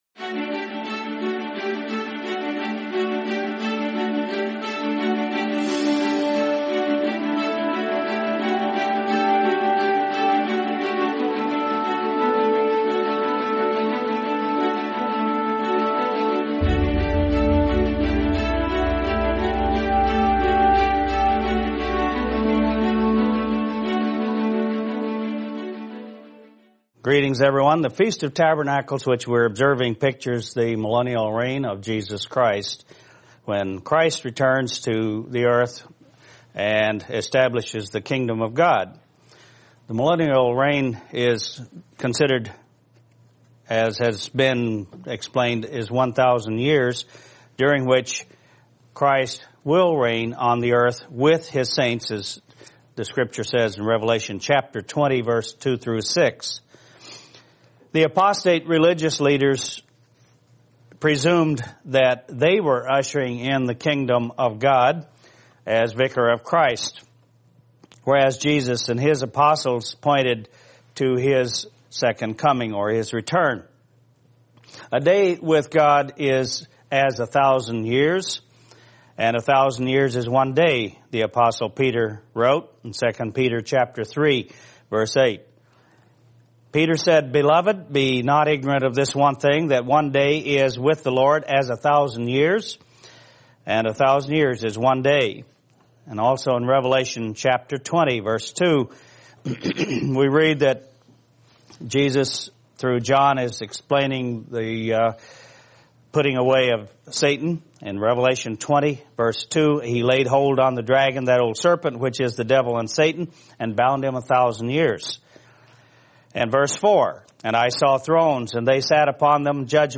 Sermon The Kingdom of Heaven